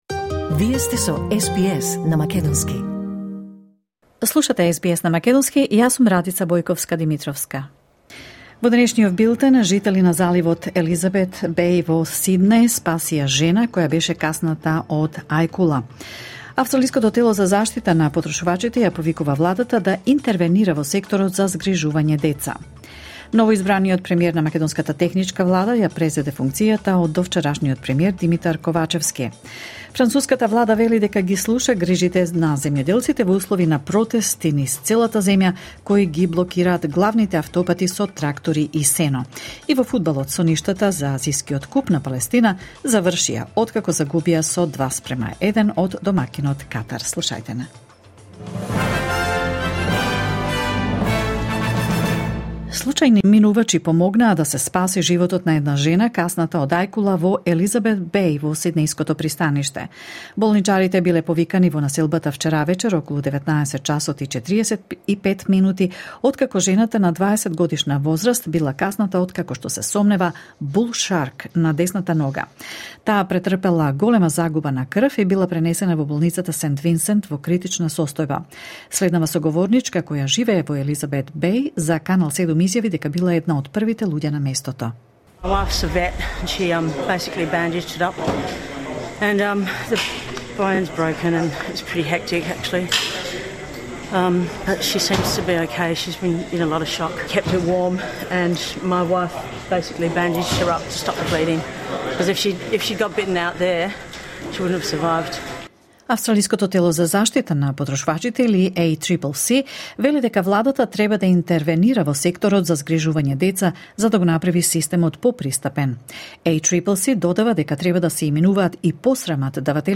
SBS News in Macedonian 30 January 2024